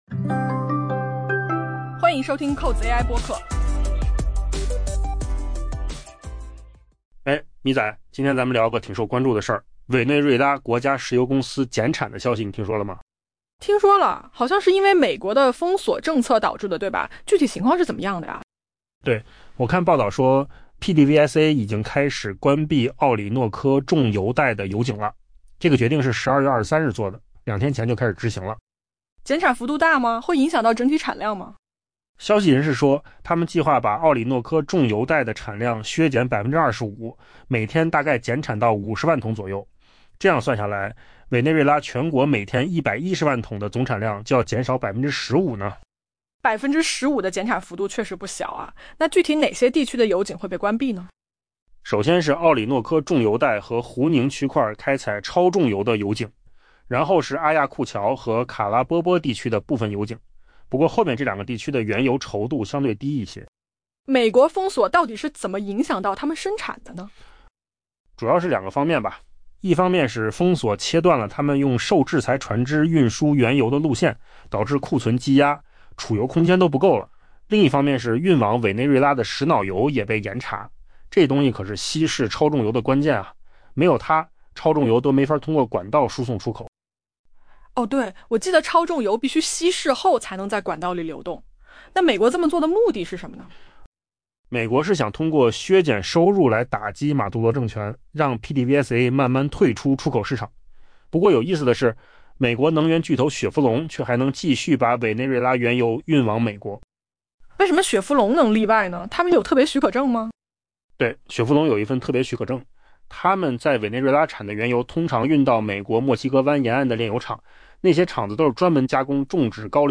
AI 播客：换个方式听新闻 下载 mp3 音频由扣子空间生成 据知晓委内瑞拉国家石油公司 （PDVSA） 计划的消息人士向彭博社透露， 由于美国的封锁限制了原油运输并导致储油空间告急，委内瑞拉已开始关闭奥里诺科重油带 （Orinoco Belt） 生产超重油的油井。